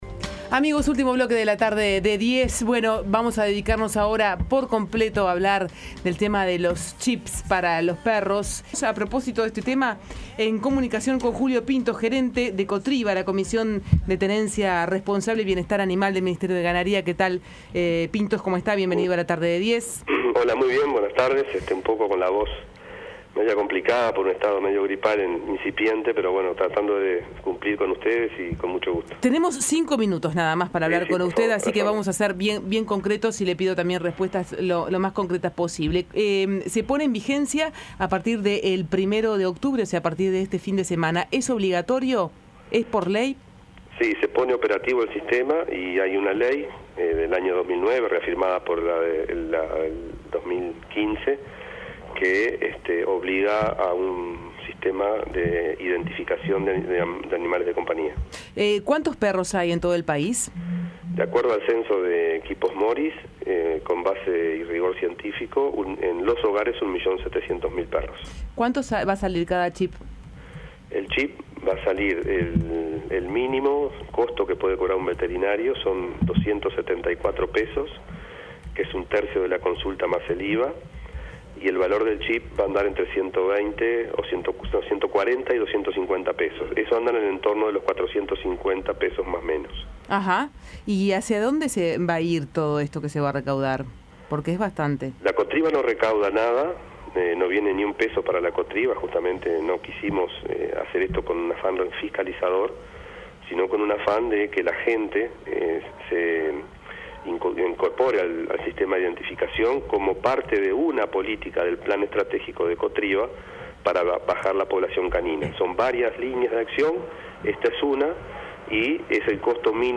El entrevistado agregó que el chip costará entre $ 140 y $ 150, y que el veterinario debe cobrar aproximadamente unos $ 274 más el IVA la consulta, siendo todo un total cercano a los $ 450.